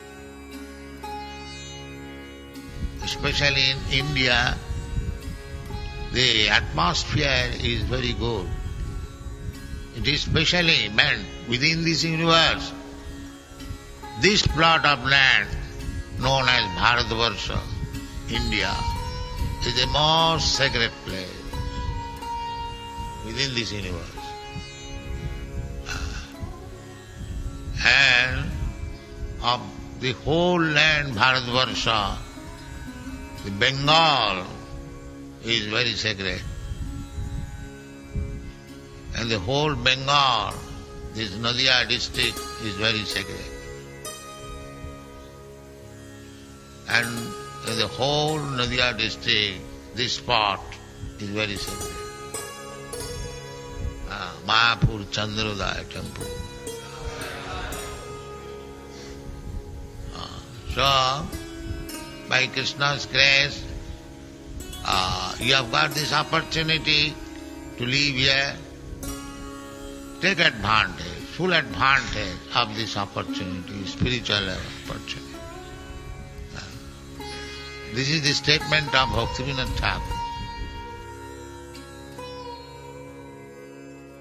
(750408 – Lecture CC Adi 01.15 – Mayapur)